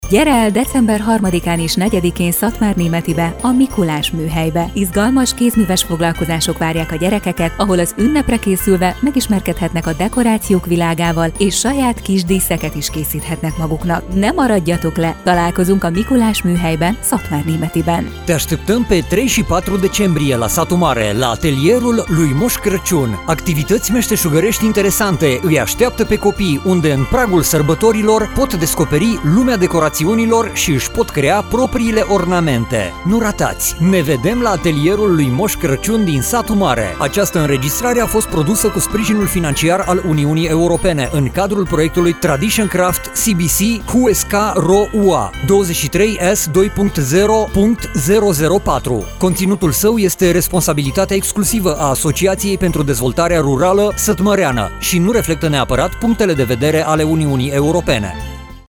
Radio spot - Atelier pentru copii - Kézműves műhely gyerekeknek